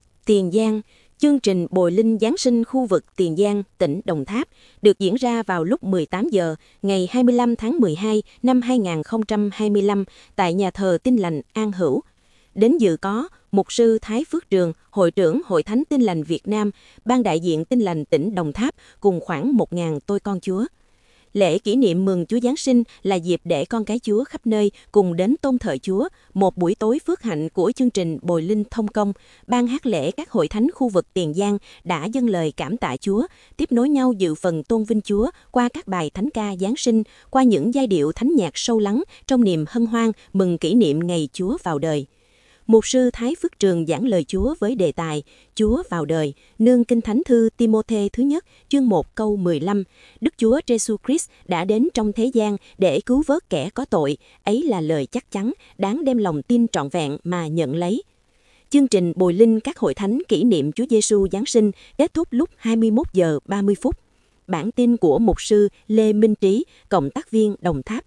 Nhà thờ Tin Lành An Hữu
Lễ Kỷ niệm Mừng Chúa Giáng sinh là dịp để con cái Chúa khắp nơi cùng đến tôn thờ Chúa, một buổi tối phước hạnh của chương trình Bồi linh Thông công, Ban Hát lễ các Hội Thánh khu vực Tiền Giang đã dâng lời cảm tạ Chúa, tiếp nối nhau dự phần tôn vinh Chúa qua các bài Thánh ca Giáng sinh, qua những giai điệu thánh nhạc sâu lắng trong niềm hân hoan mừng kỷ niệm ngày Chúa Vào Đời.